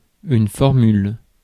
Ääntäminen
Ääntäminen France: IPA: [fɔʁ.myl] Haettu sana löytyi näillä lähdekielillä: ranska Käännös Konteksti Ääninäyte Substantiivit 1. formula kemia, matematiikka UK Suku: f .